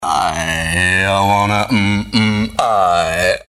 • Качество: 192, Stereo
смешные
голосовые